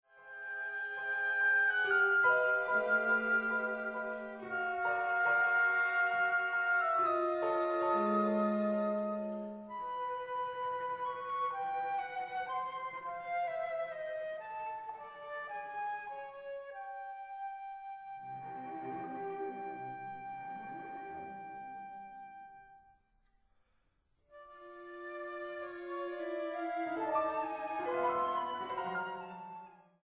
para conjunto de cámara